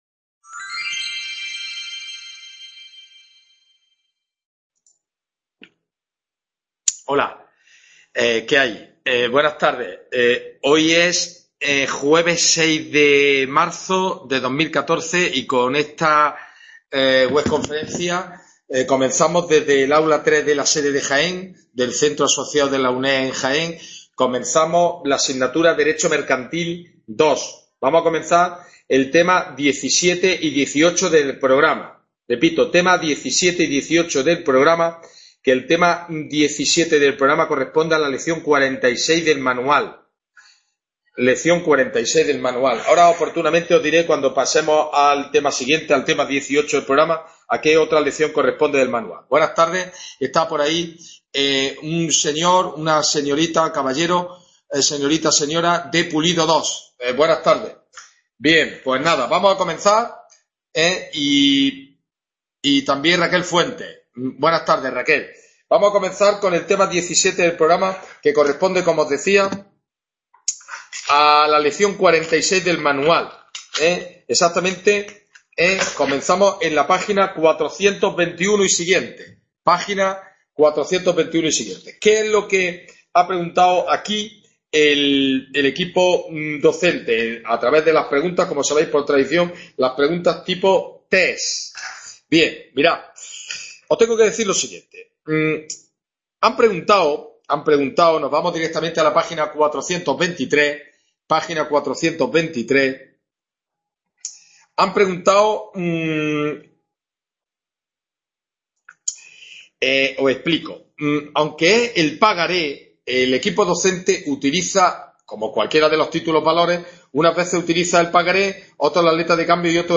WEB CONFERENCIA "Dº MERCANTIL II" (6-3-2014).